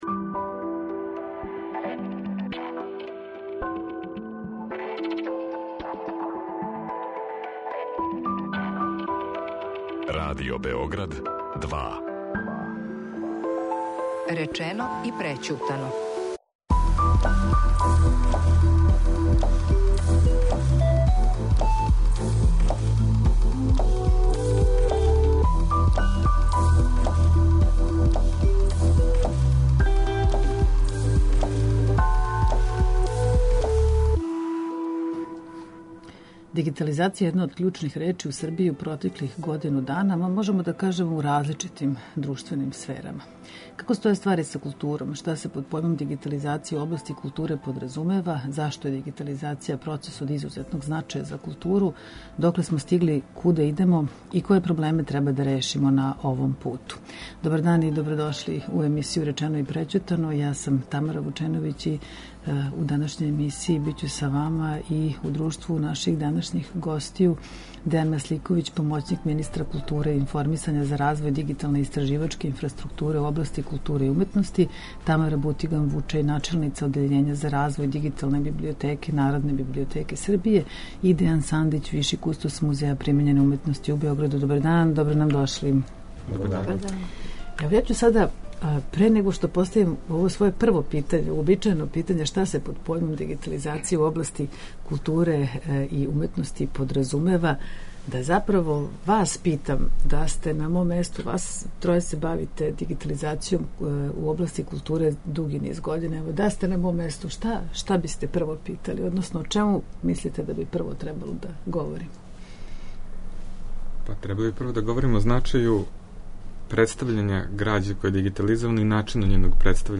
Са нама уживо: